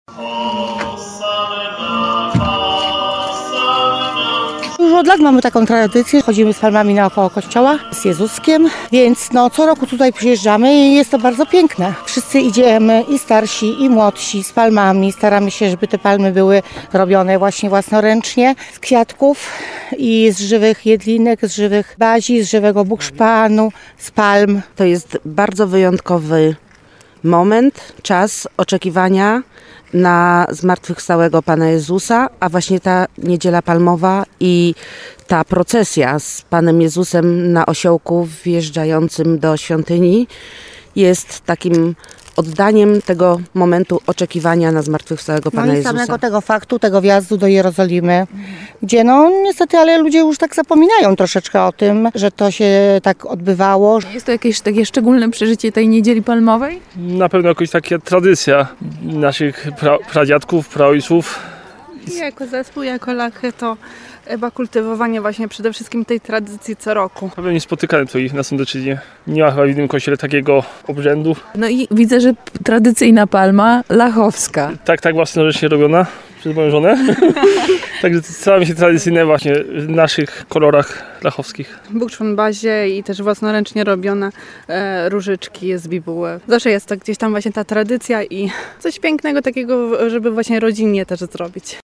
W Niedzielę Palmową (29.03) w Sądeckim Parku Etnograficznym w Nowym Sączu można było uczestniczyć w tradycyjnej procesji. Przed Mszą św. w kościele pw. Świętych Apostołów Piotra i Pawła wierni okrążyli świątynie z poświęconymi palami.